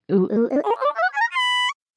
文字转语音 " attgirl8scale
描述：加工了互联网上的文本转语音样本，我在我的歌曲中用它作为人声的背景音。
Tag: 女孩 讲话 合成